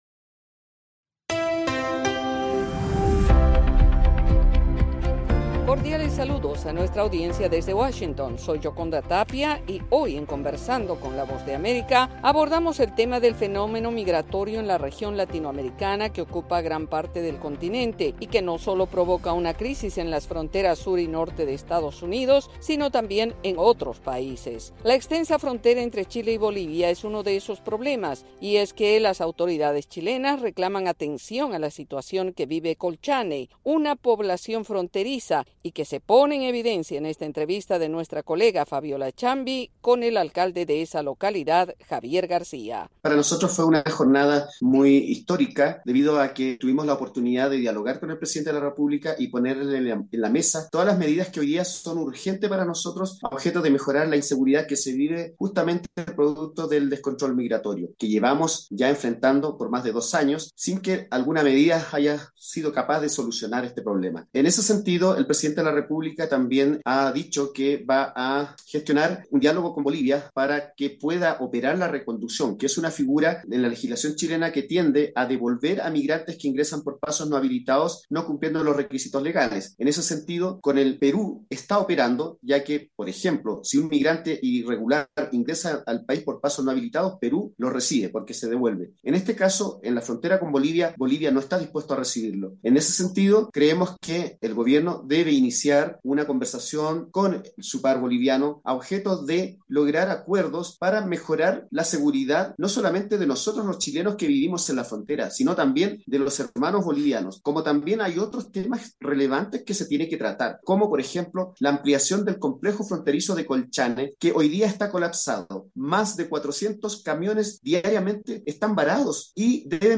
Conversamos con Javier Garcia, alcalde de la localidad chilena Colchane, en la frontera con Bolivia, explicando las dificultades que enfrentan a raíz de la migración irregular.